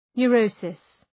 Shkrimi fonetik {nʋ’rəʋsıs}